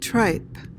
PRONUNCIATION: (tryp) MEANING: noun 1.